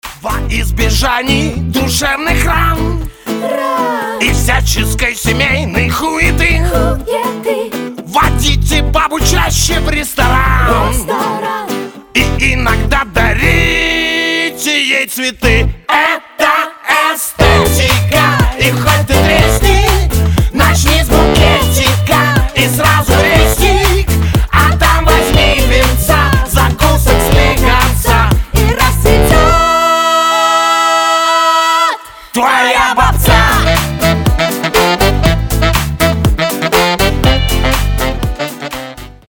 позитивные
громкие
веселые
инструментальные
озорные